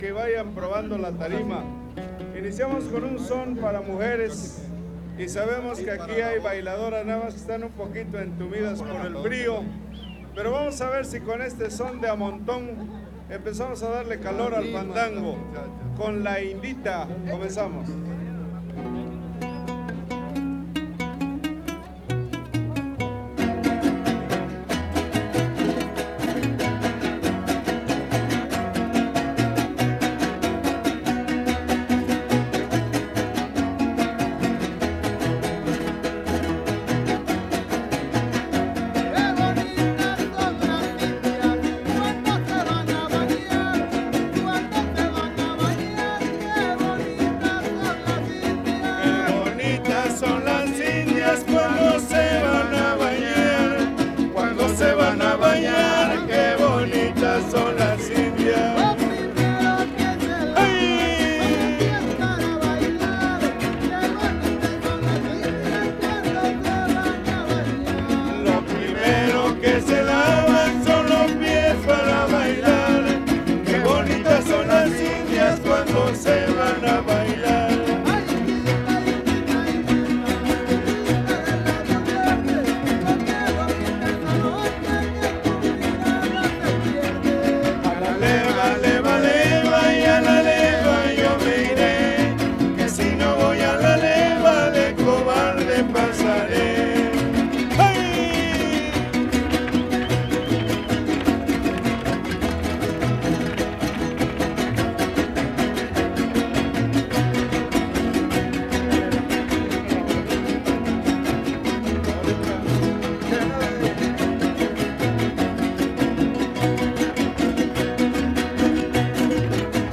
• Siquisirí (Grupo musical)
Fandango